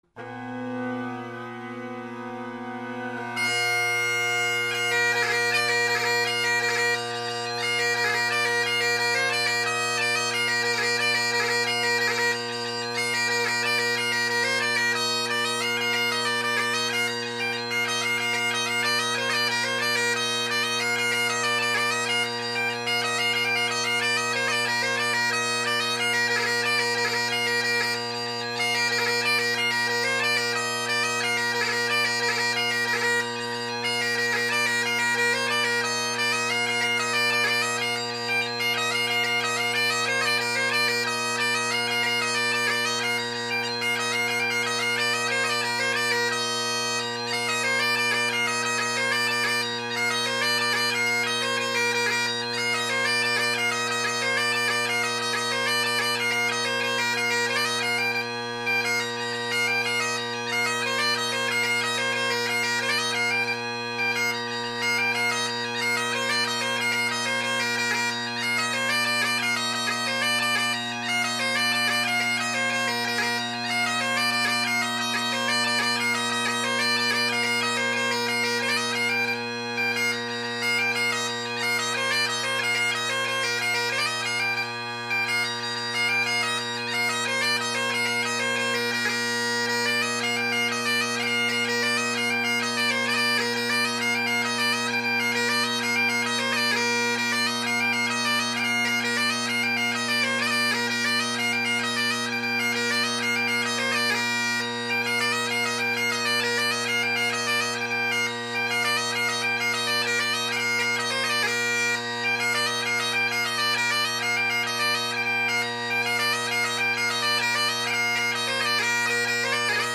Great Highland Bagpipe Solo, Tune of the Month
Willie’s Fling – Novice Tune of the Month – A great little 2 line strathspey you’re supposed to play like a reel.
Muineira de Casu – Intermediate Tune of the Month – A great 3 part “jig” from Galicia that’s a hoot to play and will challenge your Scottish based idiomatic playing like no other.
Colin Kyo with full Ezeedrone reeds and an old beat up Gilmour chanter reed in an older CK chanter.